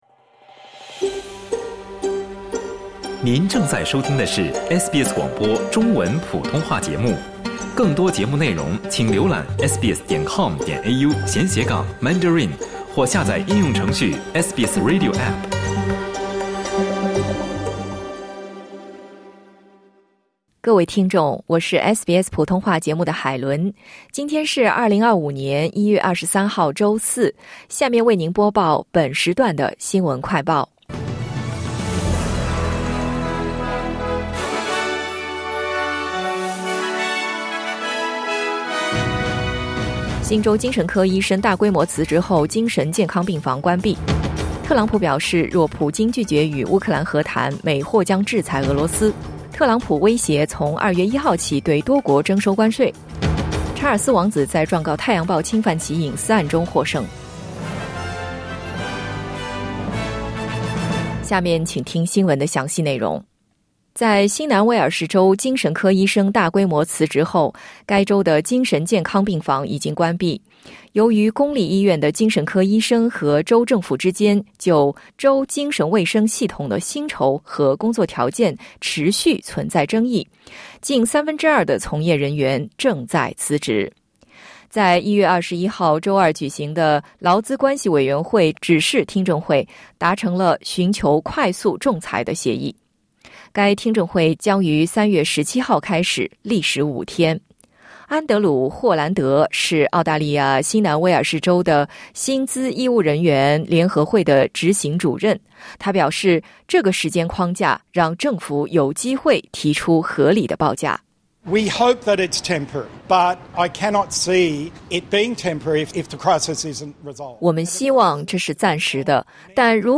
【SBS新闻快报】新州精神科医生大规模辞职后精神健康病房已关闭